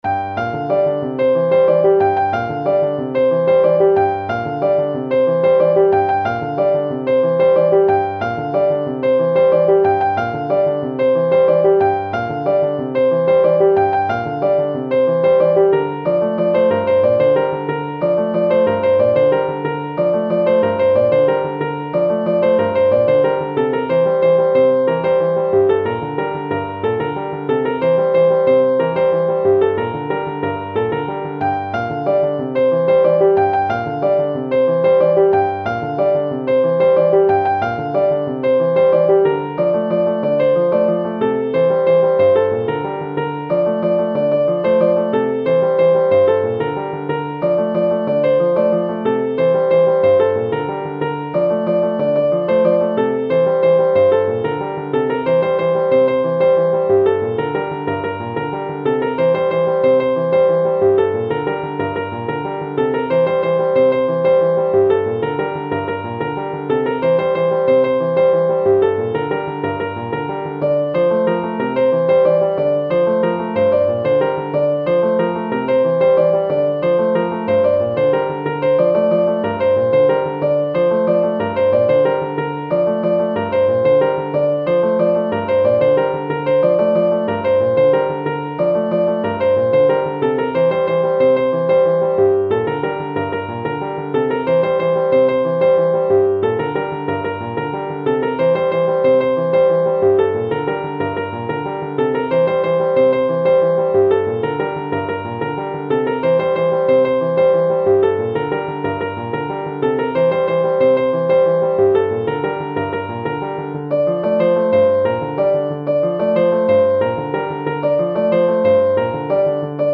محلی جنوبی